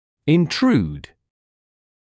[ɪn’truːd][ин’труːд]внедрять, проводить интрузию (зуба)